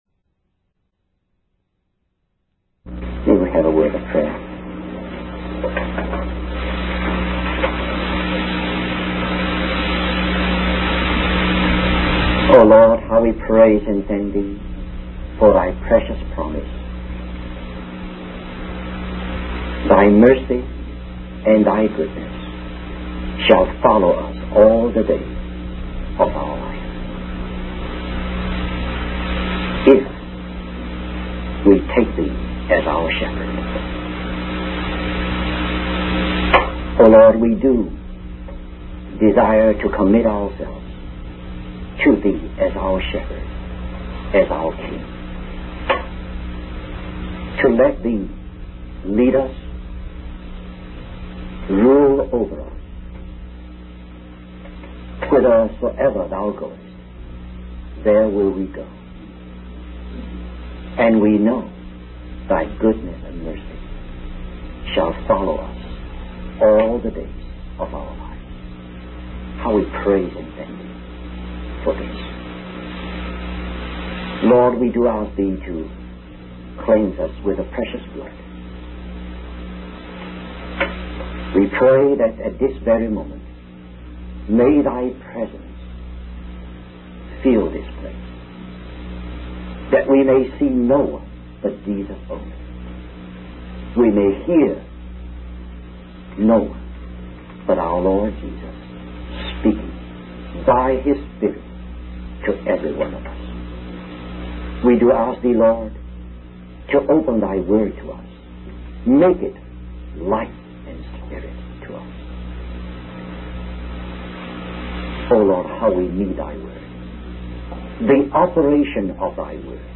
In this sermon, the preacher focuses on the themes of creation and redemption in the book of Revelation. He explains that after completing redemption, Jesus ascended to heaven and received the title deed of the world from God.